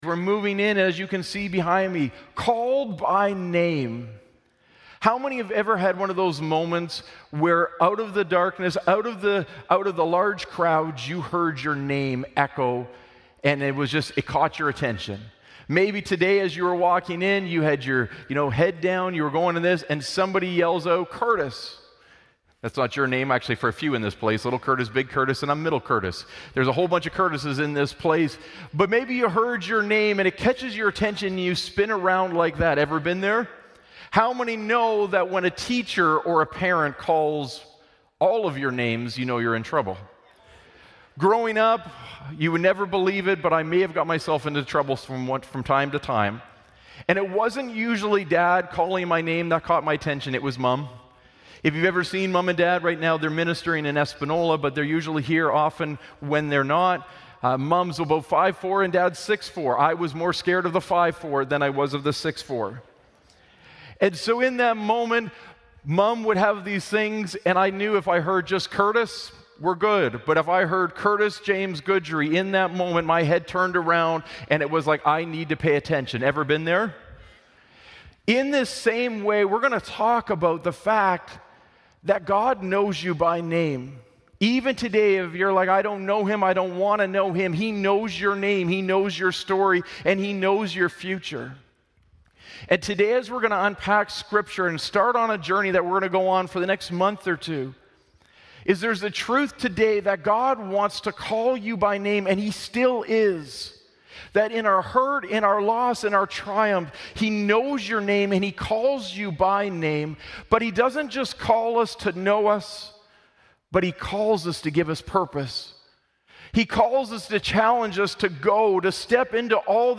Glad Tidings Church (Sudbury) - Sermon Podcast